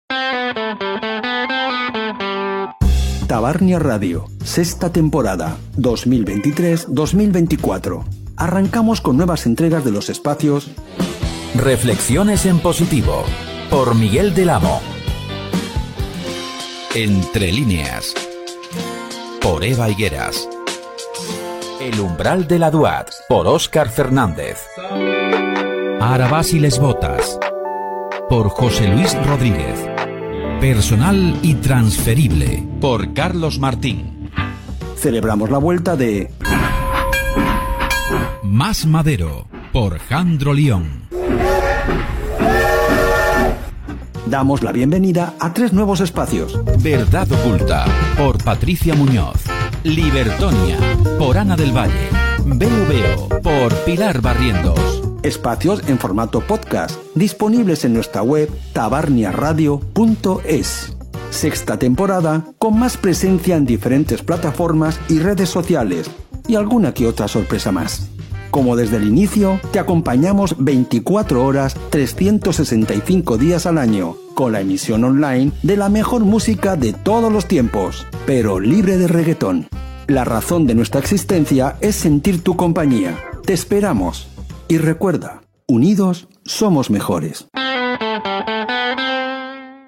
Anunci de l'inici de la sisena temporada amb alguns dels espais que la formaran
Extret del canal de Tik Tok de Tabarnia Radio